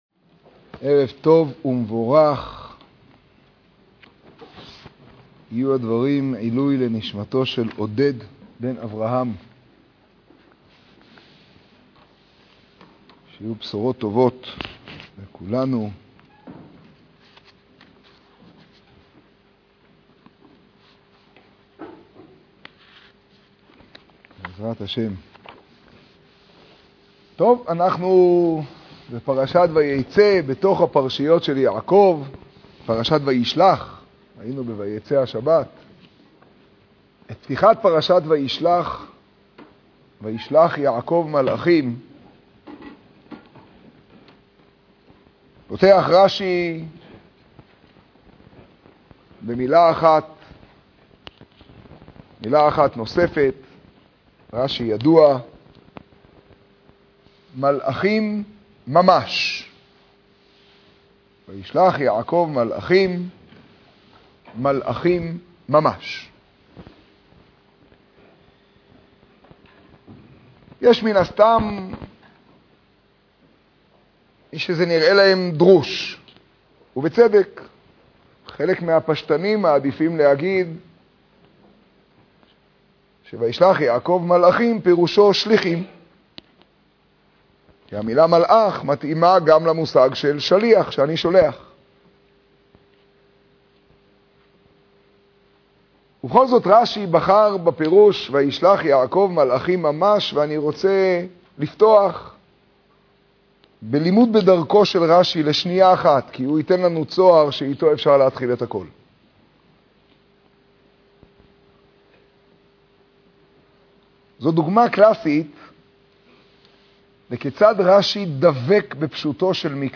השיעור בירושלים, פרשת וישלח תשעב.